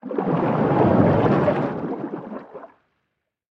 Sfx_creature_arcticray_swim_slow_03.ogg